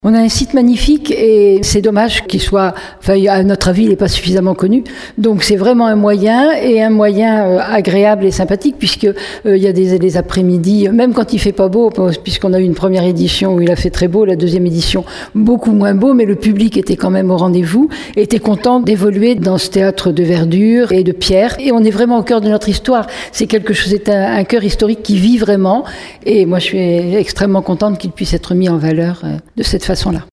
De bon augure pour la maire de Surgères Catherine Desprez très attachée à la mise en valeur du patrimoine, du château et de ses remparts :